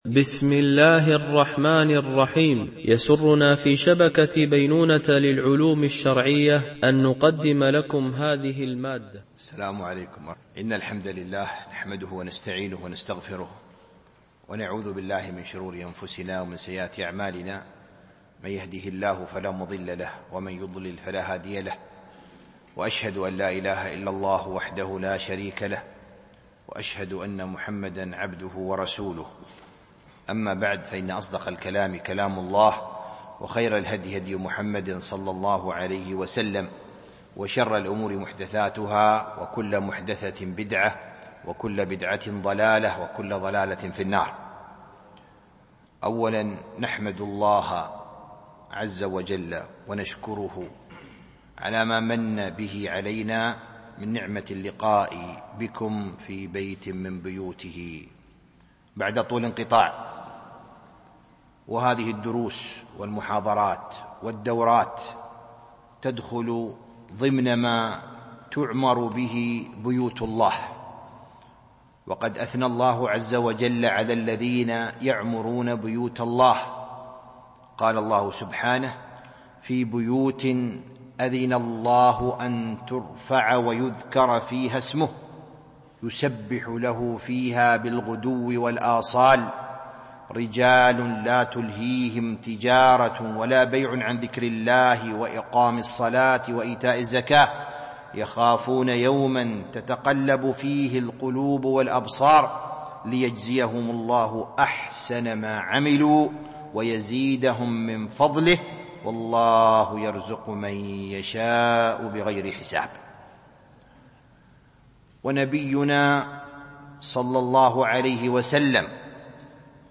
المحاضرة الافتتاحية: الصناعة الفقهية